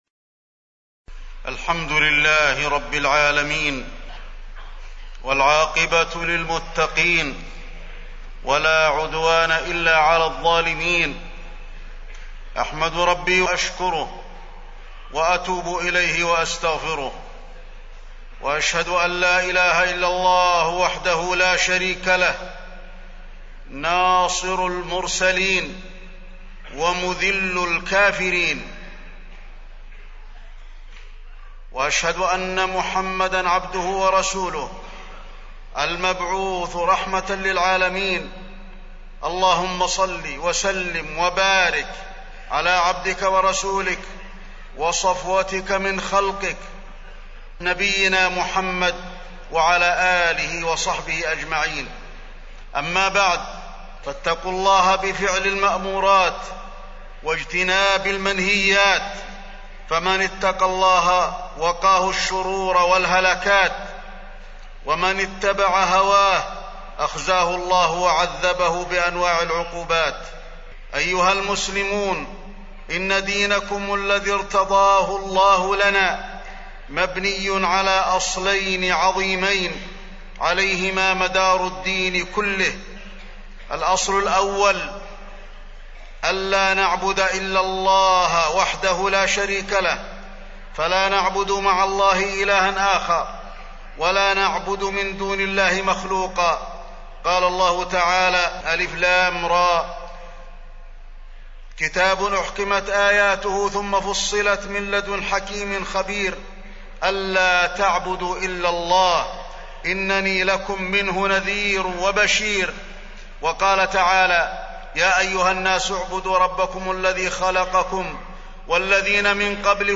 تاريخ النشر ٢٧ ذو الحجة ١٤٢٦ هـ المكان: المسجد النبوي الشيخ: فضيلة الشيخ د. علي بن عبدالرحمن الحذيفي فضيلة الشيخ د. علي بن عبدالرحمن الحذيفي حقوق النبي عليه السلام